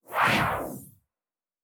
Synth Whoosh 1_3.wav